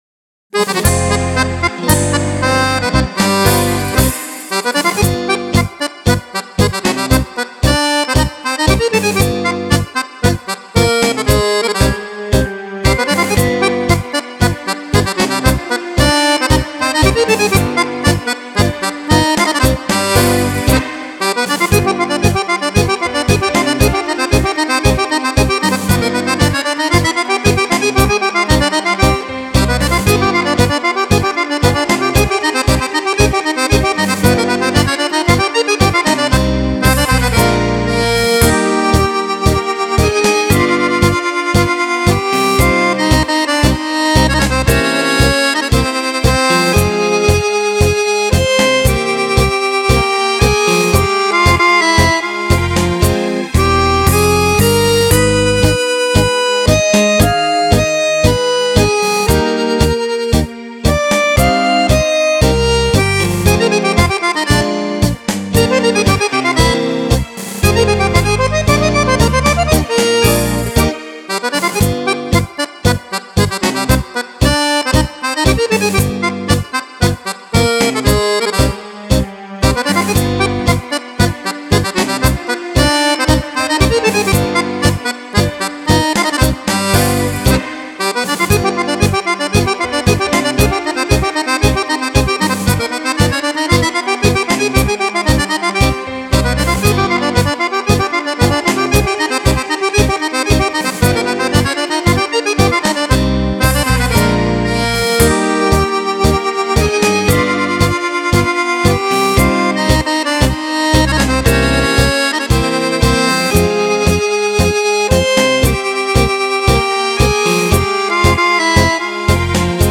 Tango
10 ballabili per Fisarmonica
Fisarmonica
Chitarre